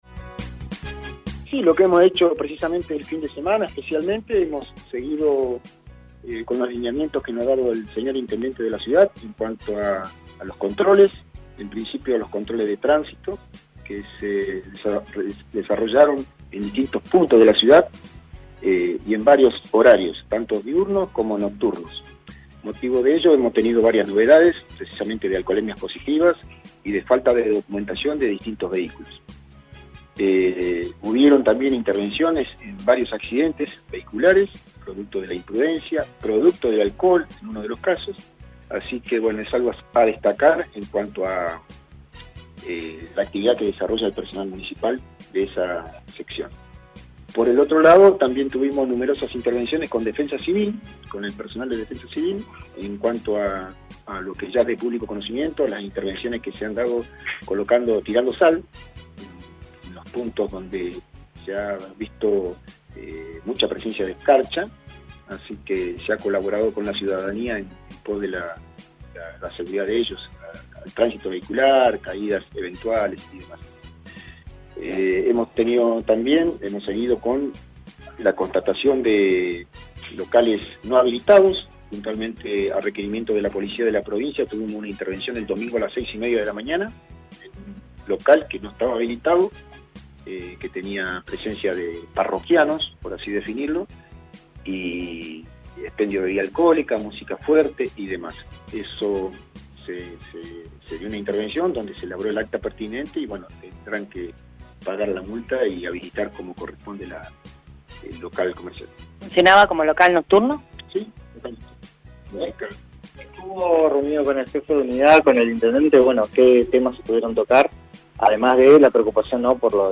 El recientemente nombrado secretario de Control Urbano y Operativo, Miguel Gómez, tuvo su primer encuentro con la prensa en una conferencia acerca de sus primeros días a cargo de esa secretaría.